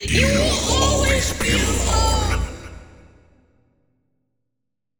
Sound was an important part of the game so the next day we spent recording audio such as screams, whispers and general voice lines to give the monsters as all three were unique in their own way.
A woman screaming for help - Will you be the good Samaritan who will save her? A croaky rasping voice telling you it's a trap - Will you listen?
A normal voice trying to confuse you - makes you feel disorientated.
The sounds when put together sound amazing and does a great job at making you feel insecure and confused.
It's very threatening and disturbing and really makes you feel uneasy when playing.